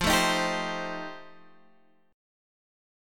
F9 chord